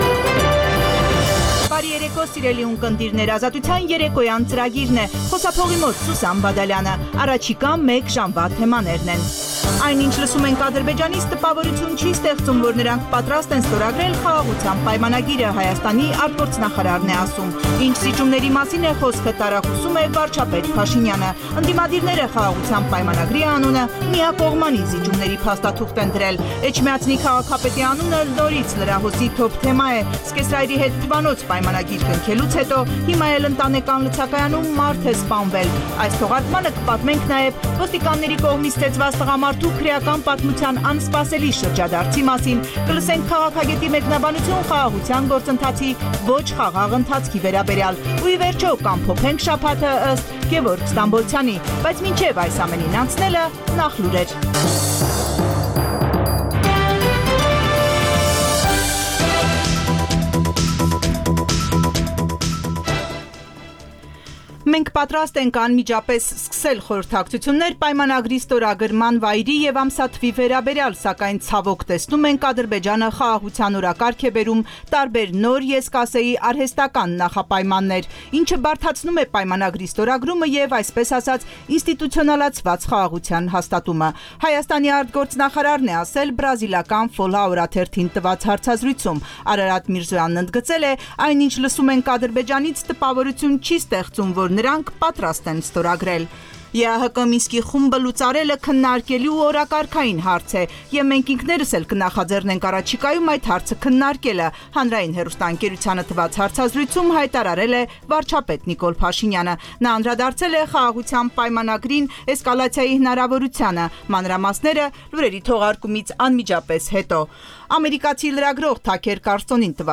«Ազատություն» ռադիոկայանի օրվա հիմնական թողարկումը: Տեղական եւ միջազգային լուրեր, ռեպորտաժներ օրվա կարեւորագույն իրադարձությունների մասին, հարցազրույցներ, մամուլի տեսություն: